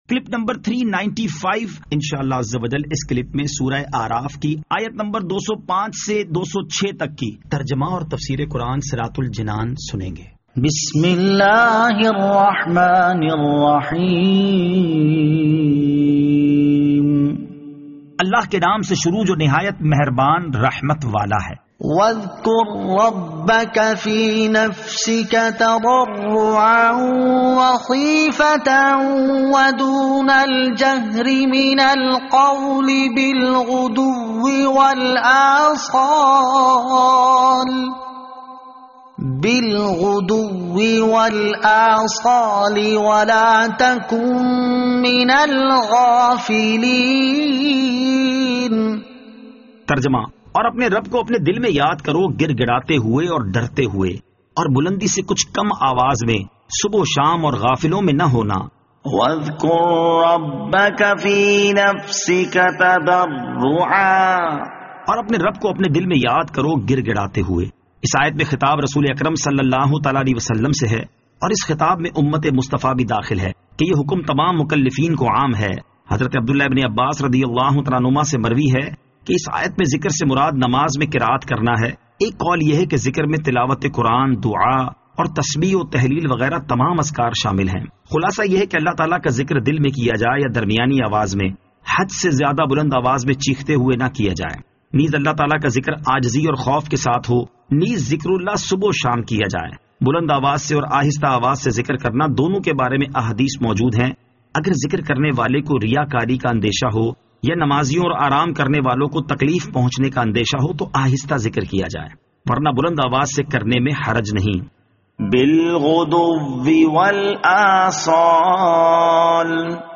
Surah Al-A'raf Ayat 205 To 206 Tilawat , Tarjama , Tafseer
2021 MP3 MP4 MP4 Share سُوَّرۃُ الْاَعْرافْ آیت 205 تا 206 تلاوت ، ترجمہ ، تفسیر ۔